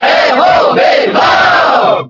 Category:Crowd cheers (SSBB) You cannot overwrite this file.
R.O.B._Cheer_French_SSBB.ogg